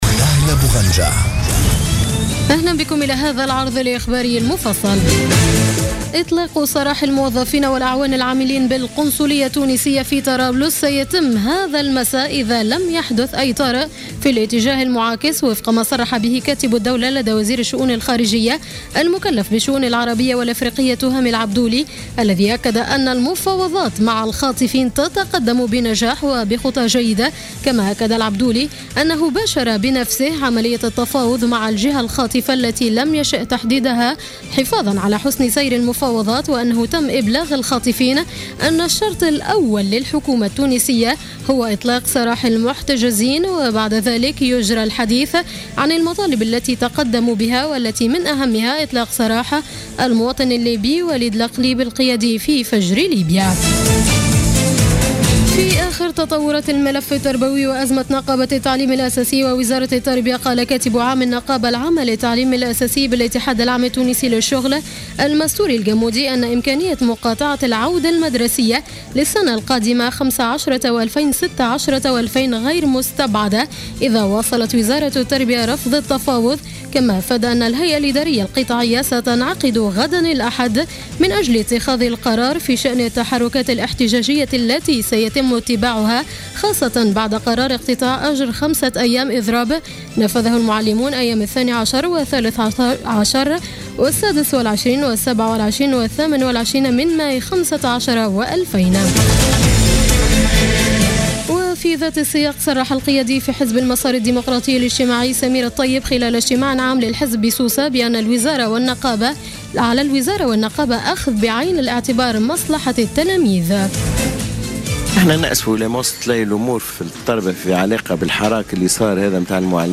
نشرة أخبار السابعة مساء ليوم السبت 13 جوان 2015